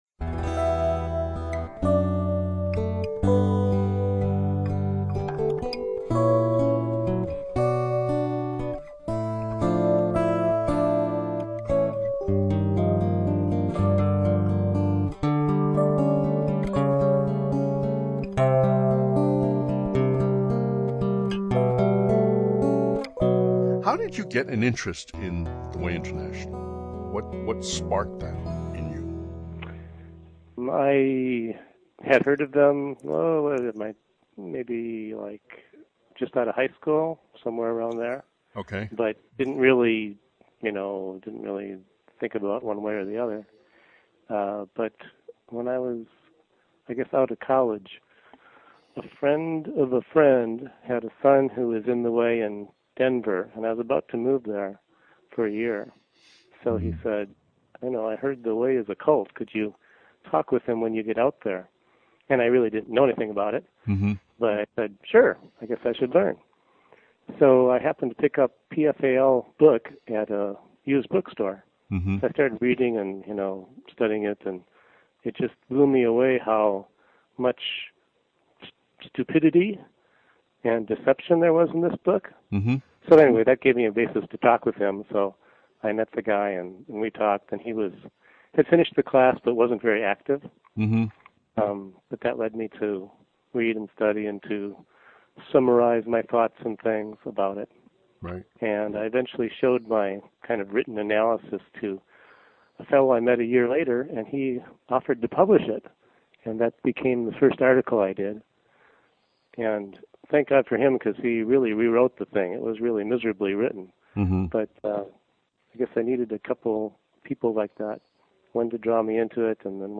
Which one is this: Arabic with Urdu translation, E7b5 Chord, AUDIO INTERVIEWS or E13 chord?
AUDIO INTERVIEWS